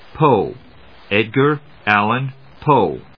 音節Poe 発音記号・読み方/póʊpˈəʊ/発音を聞く